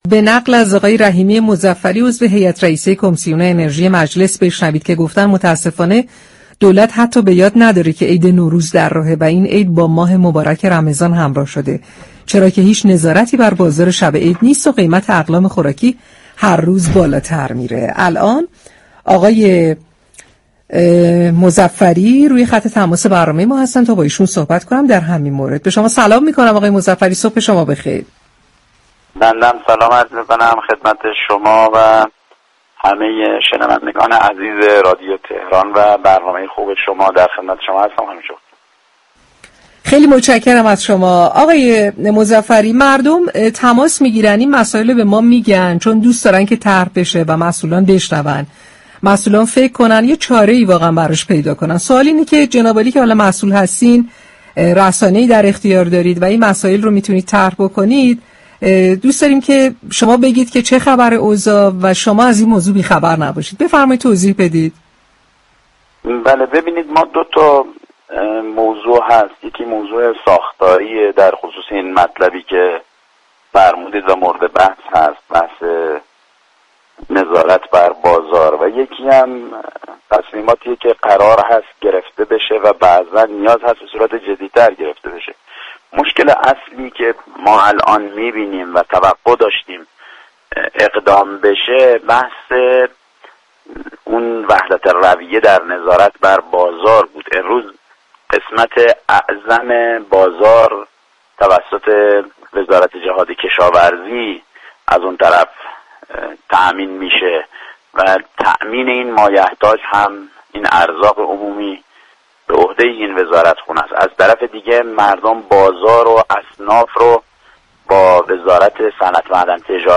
به گزارش پایگاه اطلاع رسانی رادیو تهران، عبدالعلی رحیمی مظفری عضو هیات رئیسه كمیسیون انرژی مجلس شورای اسلامی در گفت و گو با «شهر آفتاب» اظهار داشت: امروز بخش اعظم مایحتاج مردم توسط وزارت جهاد كشاورزی تامین می‌شود؛ از سوی دیگر نظارت بر بازار اصناف بر عهده وزارت صمت است.